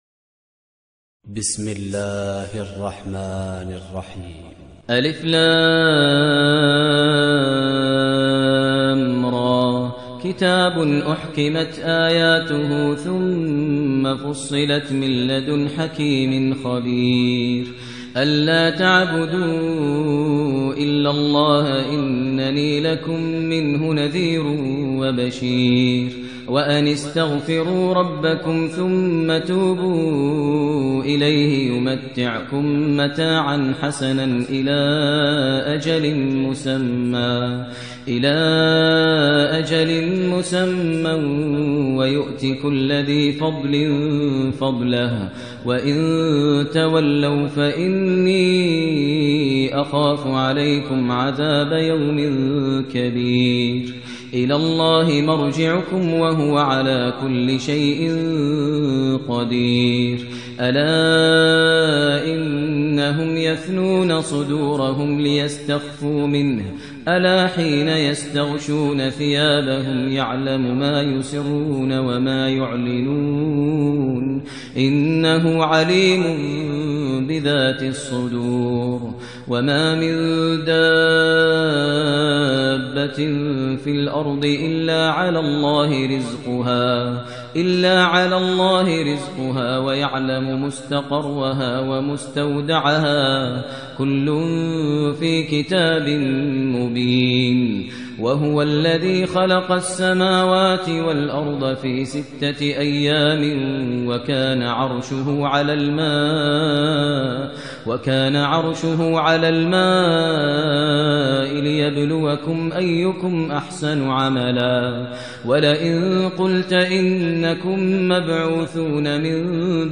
ترتیل سوره هود با صدای ماهر المعیقلی
011-Maher-Al-Muaiqly-Surah-Hud.mp3